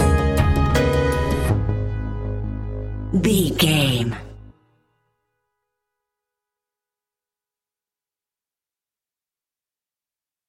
Futuristic Industrial Suspense Stinger.
Aeolian/Minor
G#
ominous
dark
eerie
electric piano
percussion
drums
synthesiser
strings
horror music
Horror Pads